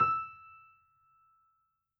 piano_076.wav